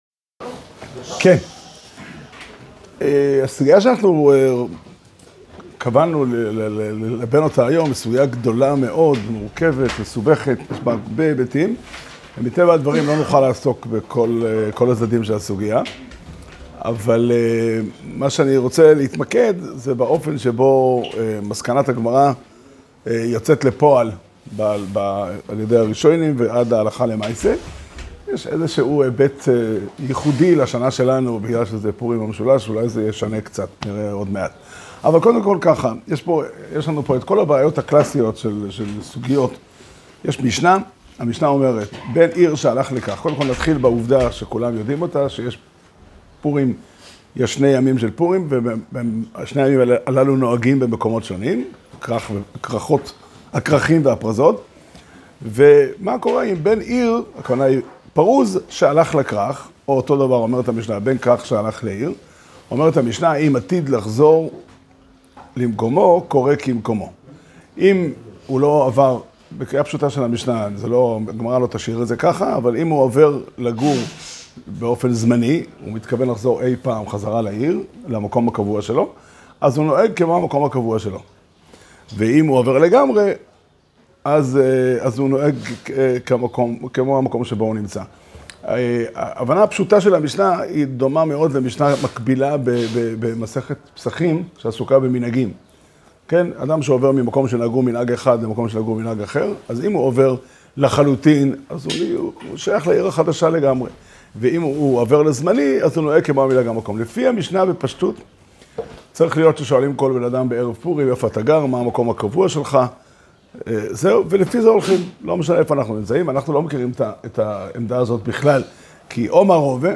שיעור שנמסר בבית המדרש פתחי עולם בתאריך ט' אדר תשפ"ה